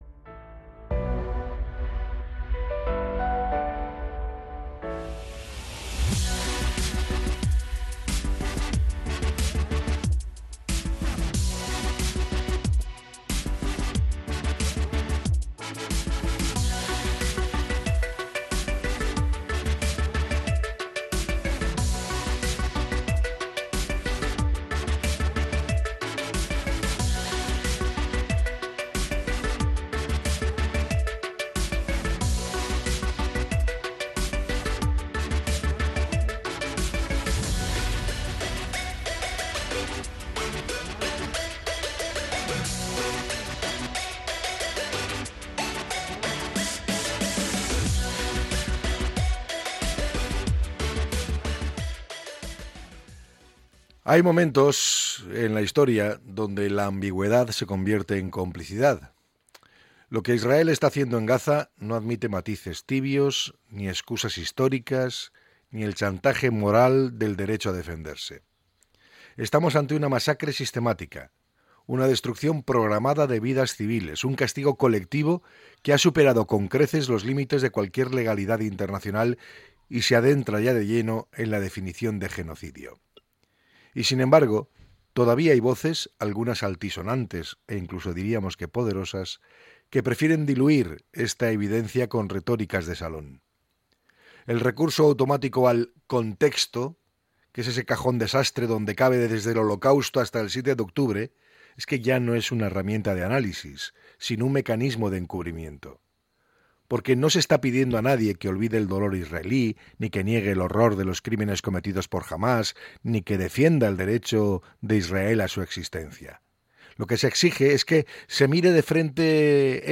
Podcast Opinión
pide hoy en un encendido comentario acabar con la equidistancia y el blanqueamiento de las acciones de Israel.